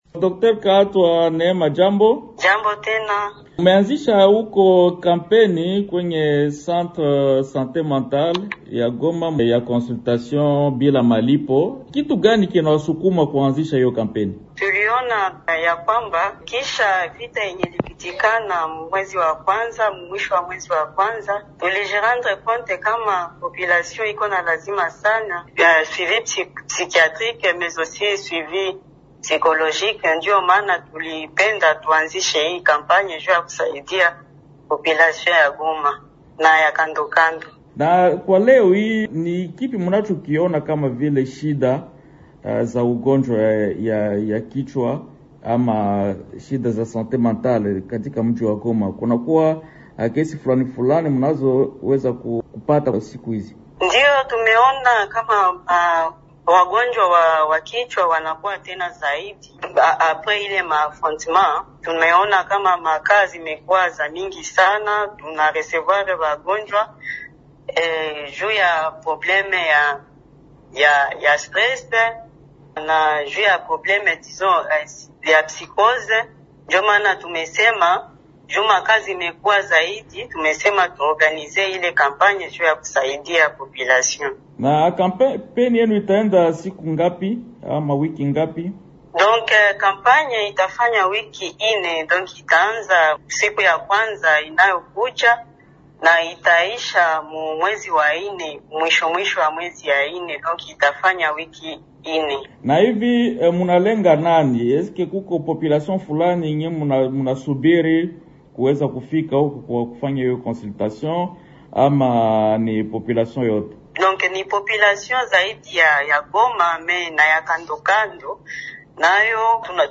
Ni katika mahojiano haya